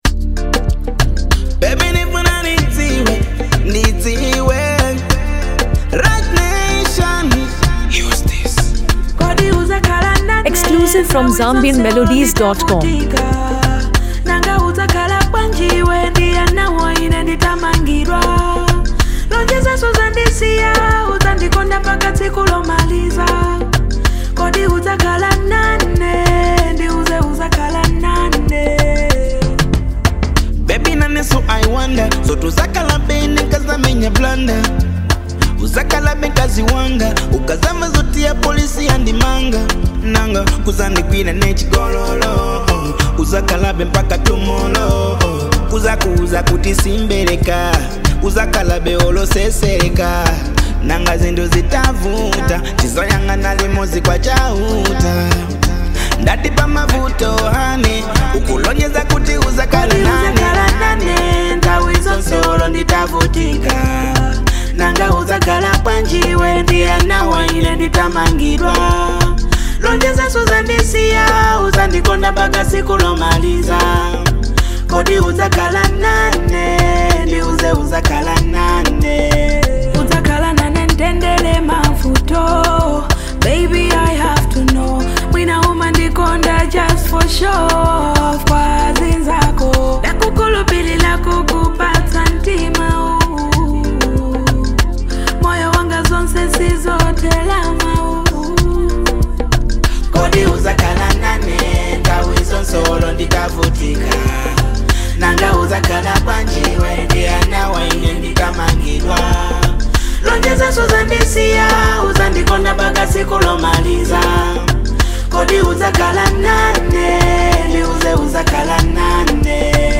Afro-pop and R&B fusion